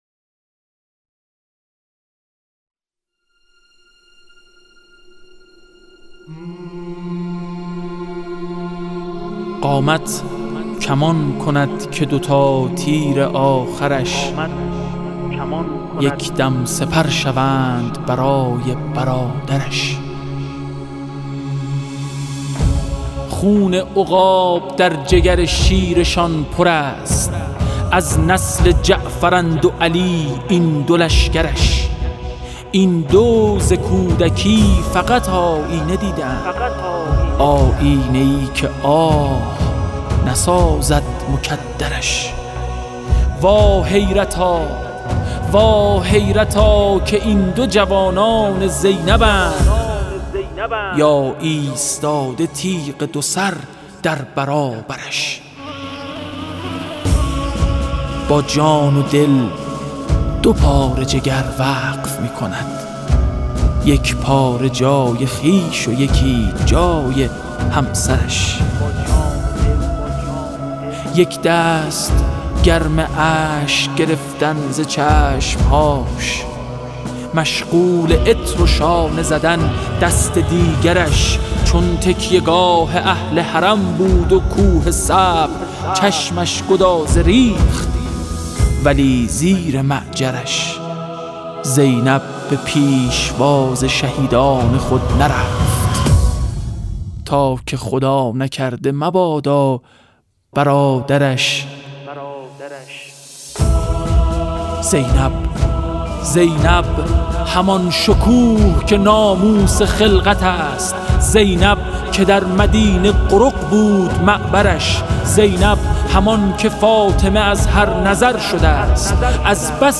شعرخوانی حمیدرضا برقعی